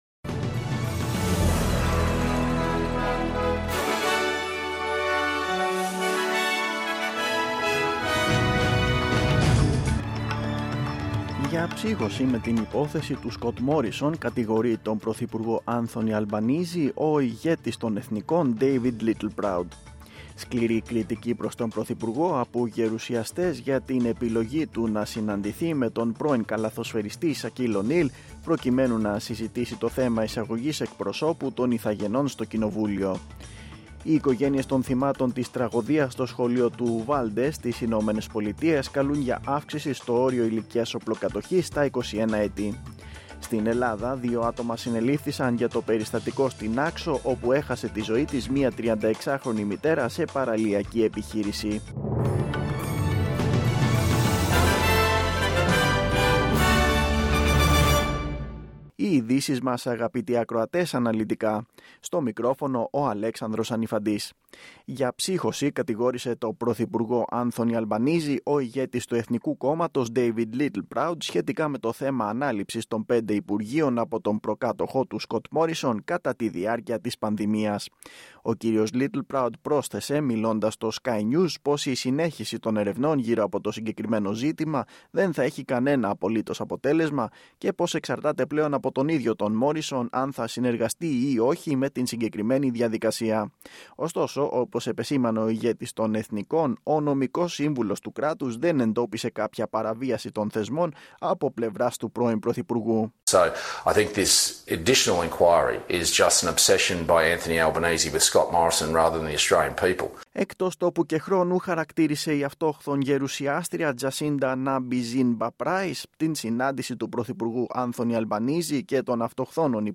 Δελτίο Ειδήσεων: Κυριακή 28.8.2022
Το αναλυτικό δελτίο ειδήσεων από το Ελληνικό Πρόγραμμα της ραδιοφωνίας SBS, στις 4 μμ.
News in Greek.